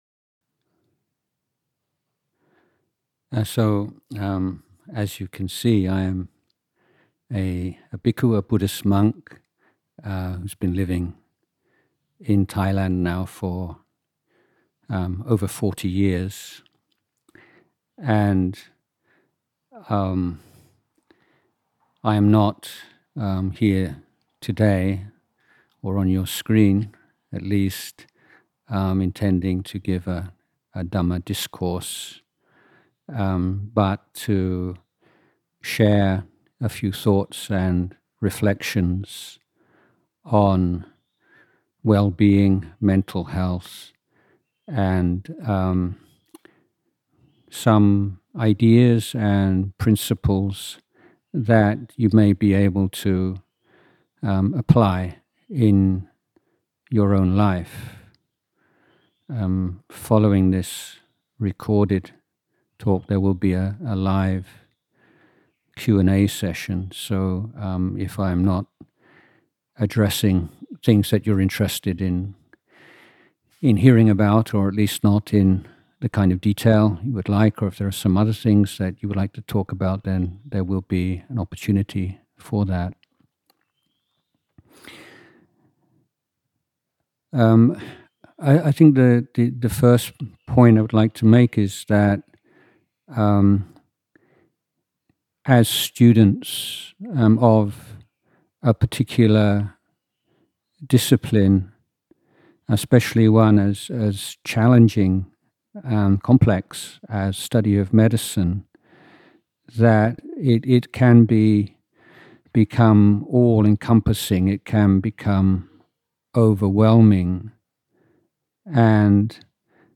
Humanistic: Inspirational Talk, CU-MEDi (Doctor of Medicine – International Program), Faculty of Medicine at Chulalongkorn University, 23 September 2021